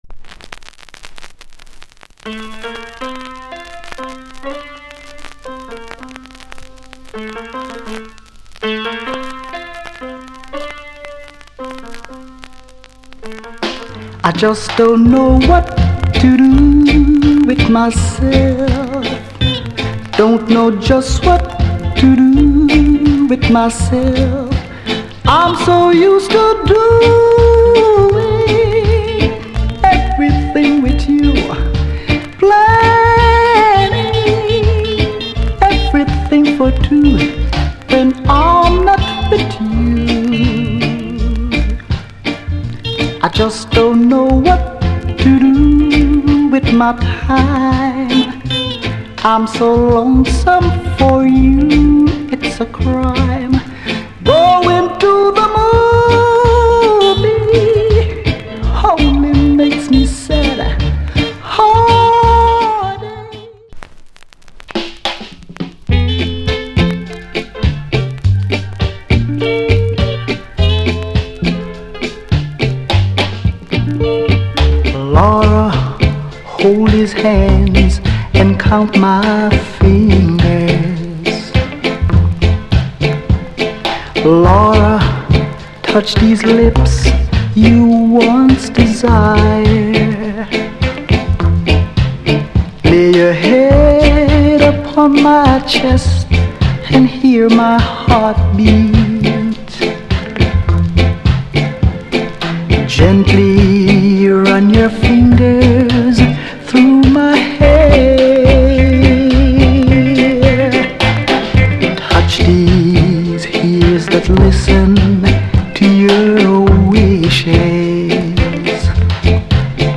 Genre [A] Reggae70sEarly [B] Early Reggae / Male Vocal
* Nice Vocal Tune with Both Side ** Good Condition !